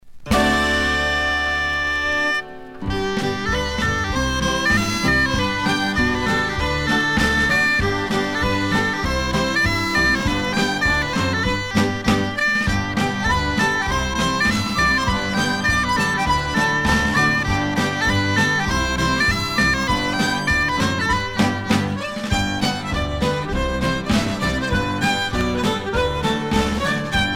danse : step-dance
Pièce musicale éditée